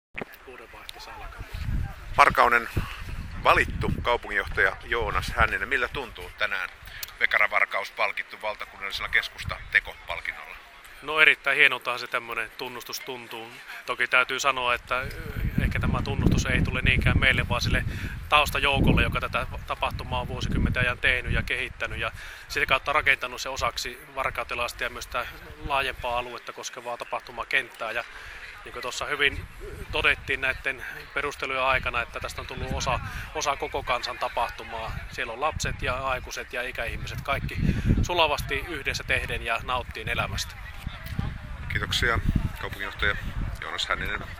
Varkauden valittu kaupunginjohtaja Joonas Hänninen: ”Hienolta tuntuu…”